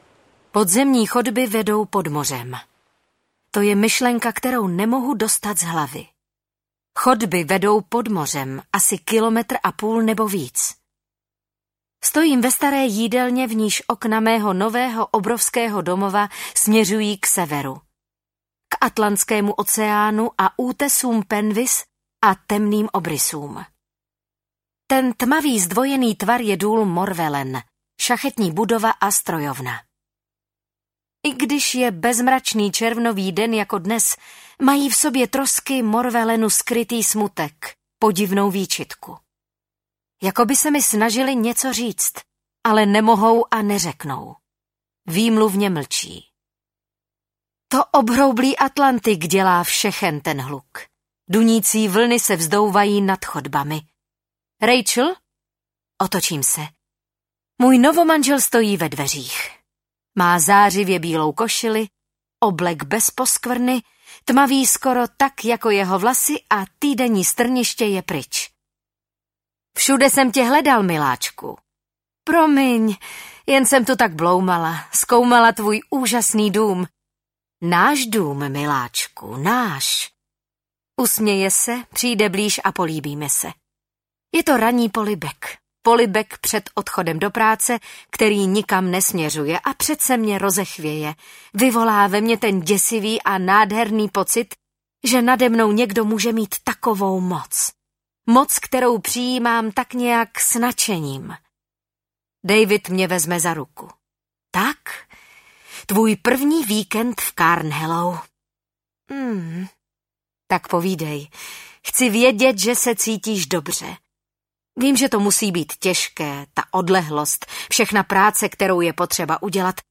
Dítě ohně audiokniha
Ukázka z knihy